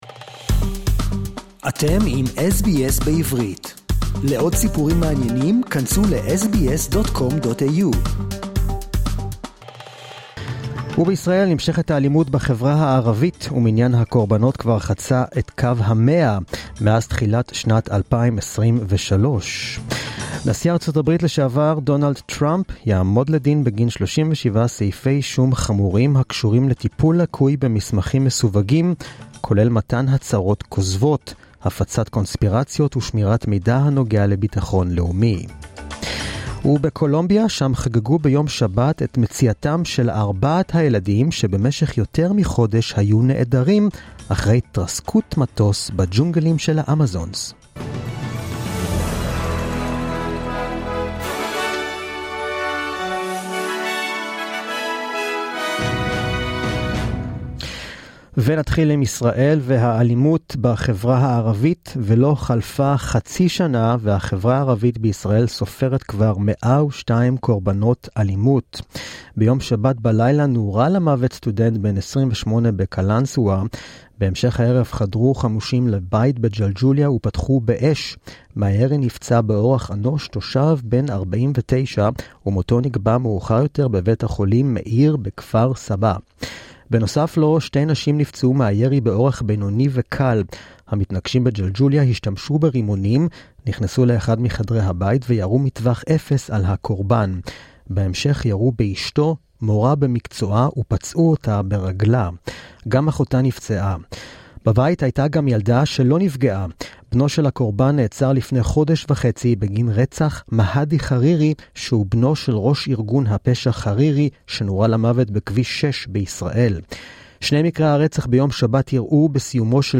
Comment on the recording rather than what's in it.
The latest news in Hebrew, as heard on the SBS Hebrew program